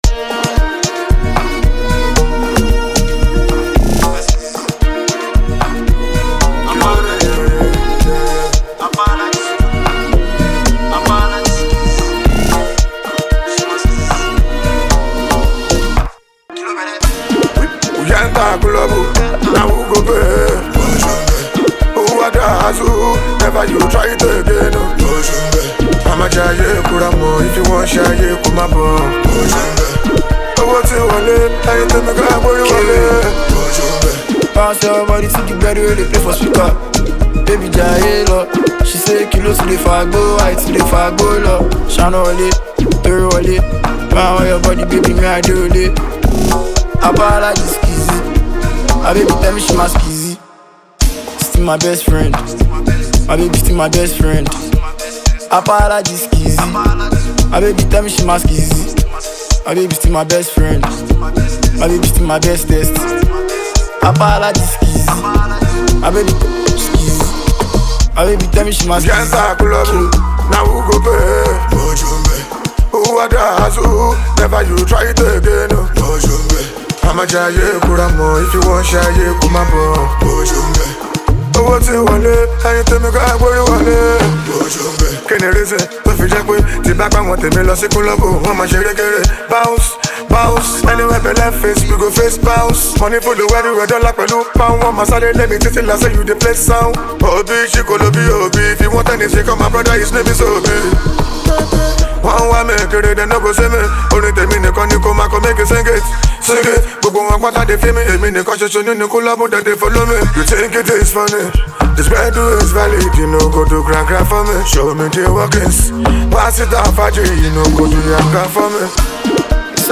A Vibrant Fusion of Street Rhythm and Modern Flair
Nigerian street-pop
signature gritty vocals and swagger
a smooth, melodic contrast